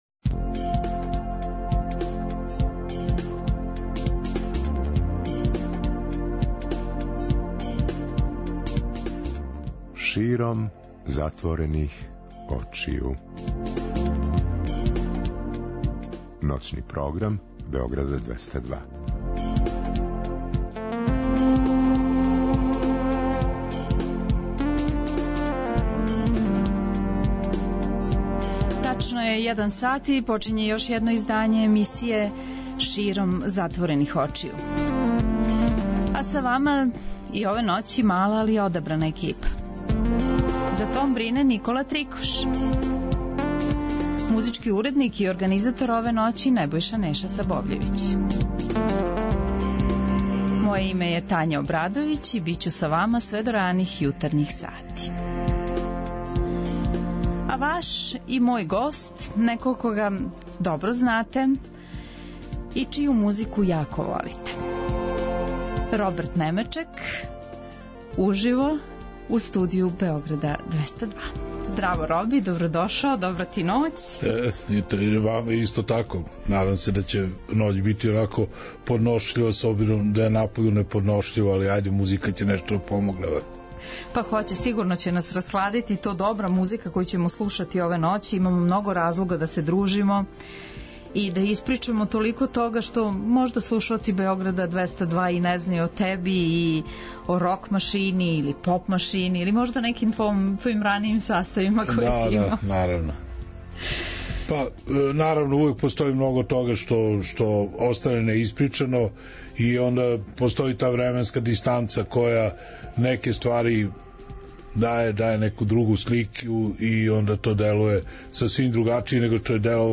Слушаоци ће имати прилику да преслушају композиције са овог албума, али и да чују много тога о времену када су ове нумере настајале.